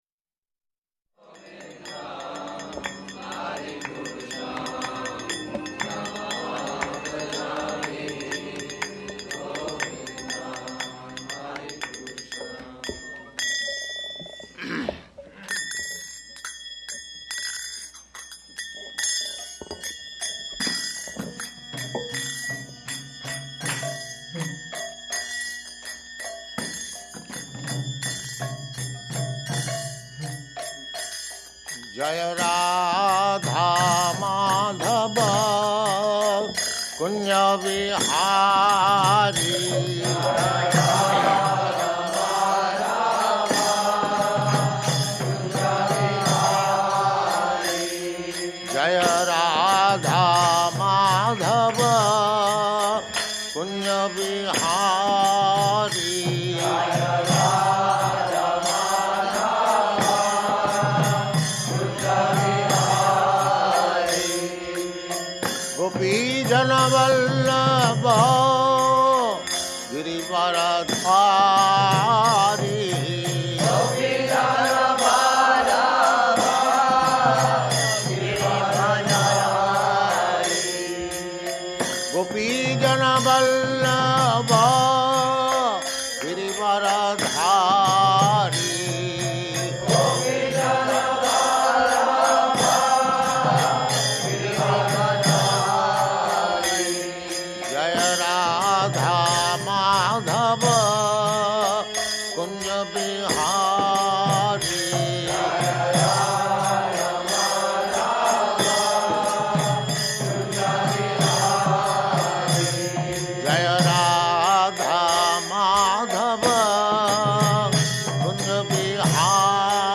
Location: Los Angeles
[ Govindam prayers playing]